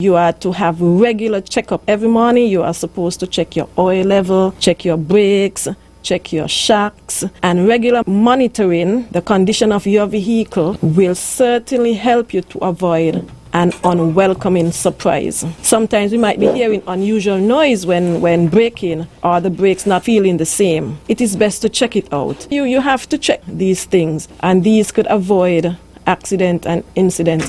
During a discussion featuring the Royal St. Christopher and Nevis Police Force, representatives of the force encouraged the public to ensure their vehicle are regularly maintained and to familiarize themselves with the various controls, signs and symbols.